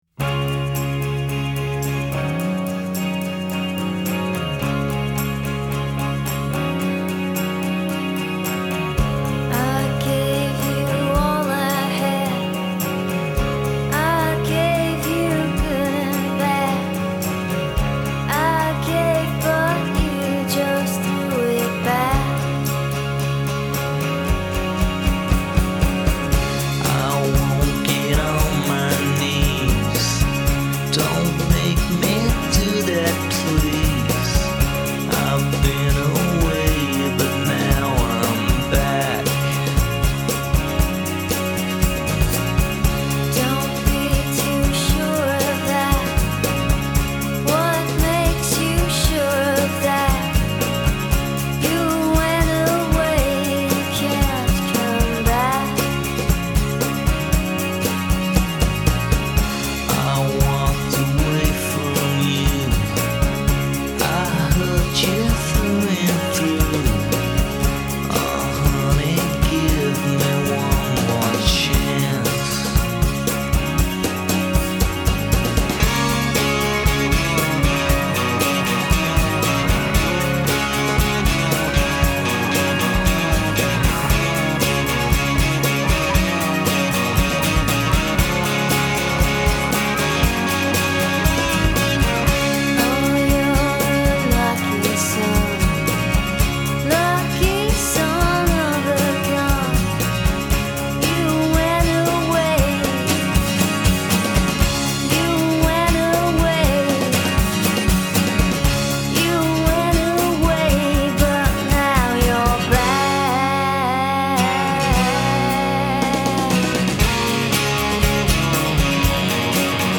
mostly-acoustic